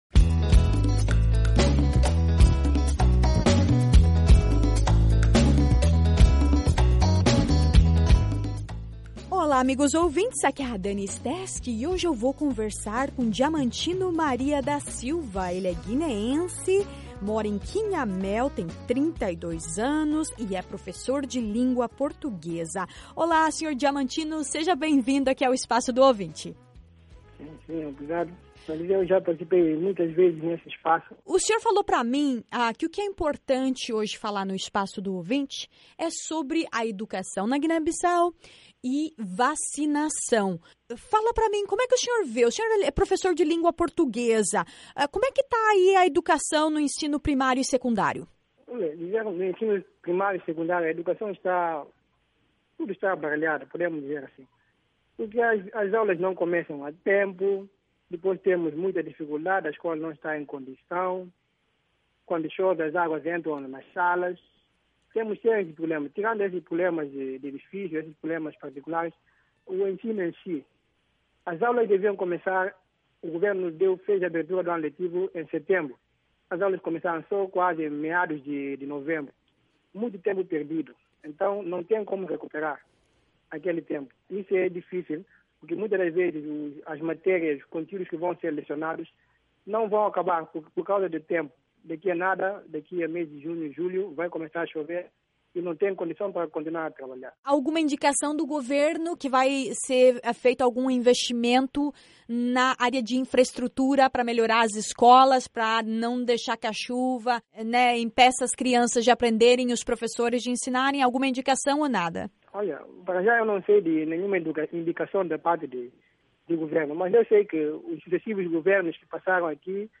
Na entrevista desta semana para o Espaço do Ouvinte